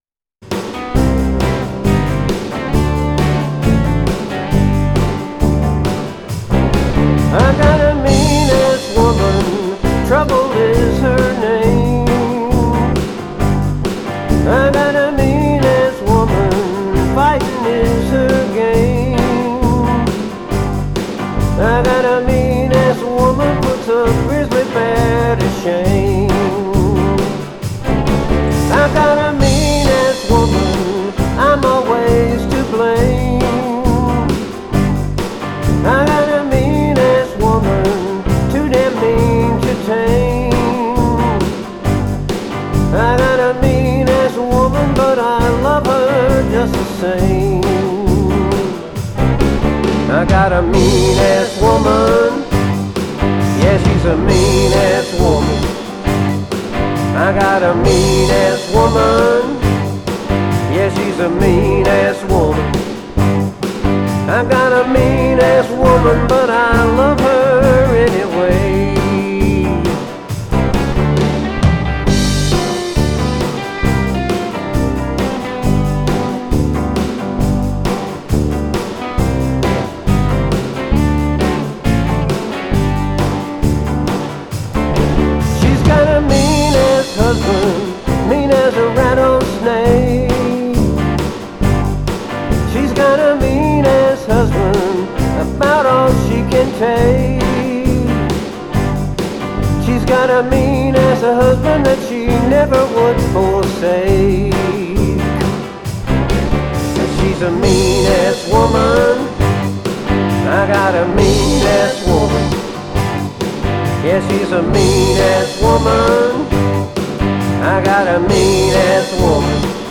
The Living Room Floor Band
So, I'm in old-school mode with my Portastudio and I like it.
But probably no one has had as much fun as me doing these recordings in my living room floor.